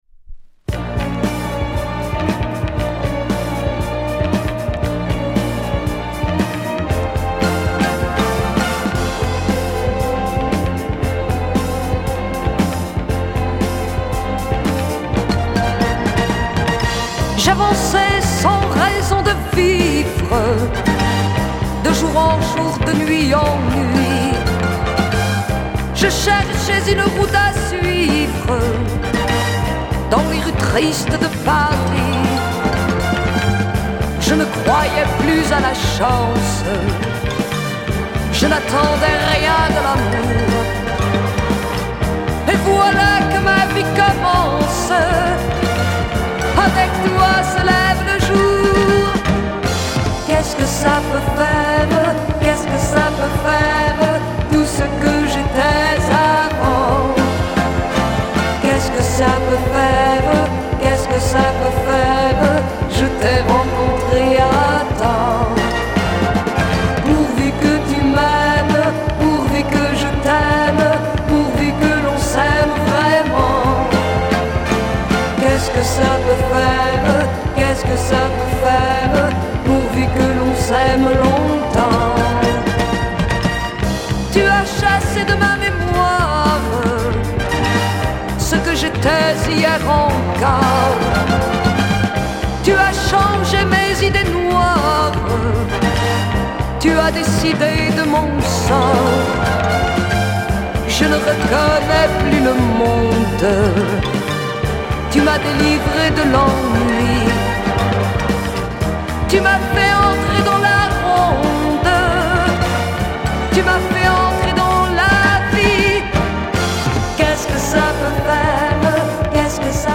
French Private female pop soul
Very obscure and unkown French female singer